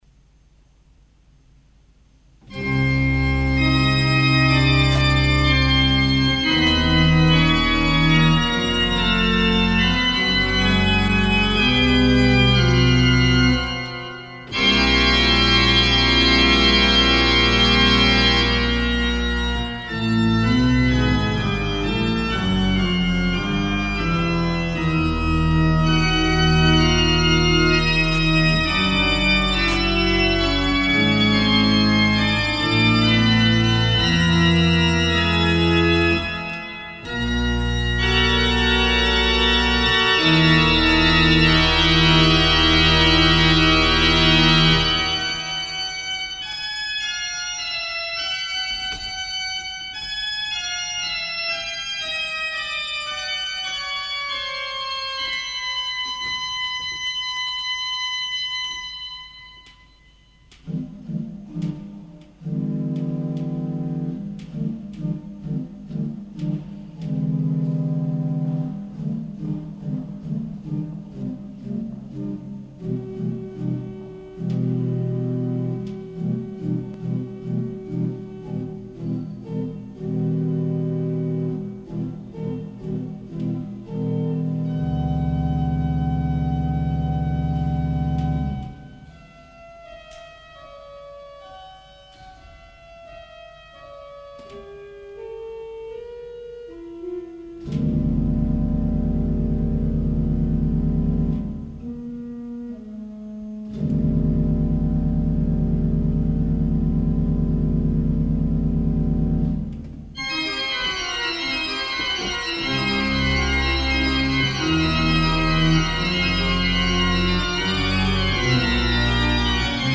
für Orgel [ 1979 ]Dauer ca. 10’00“Notenkauf [Edition Tilli]
eine lebendige Rhythmik mit häufig wechselnden Metren als Ausgangspunkt für die Gestaltung der Fantasie, und im Gegensatz dazu die melodische Chromatik des Anfangsmotivs und Fugenthemas in Verbindung mit einer frei-tonalen/frei-modalen Harmonik
Letztere ist hier wohl noch ausgespart; das tonale Zentrum „C“ dieses Stücks erscheint allerdings über weite Strecken durch achsenharmonische bzw. polytonale Bezüge relativiert.
fantasie_fuer_orgel_01.mp3